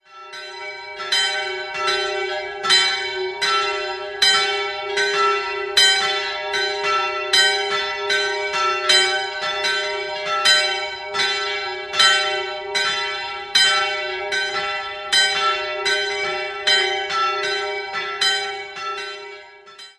3-stimmiges Geläut: f''-g''-as'' Die große Glocke wurde 1820 von Stapf in Eichstätt gegossen, die mittlere von Hamm im Jahr 1939. Die kleinste ist nur händisch zu bedienen und trägt weder Zier noch Inschriften.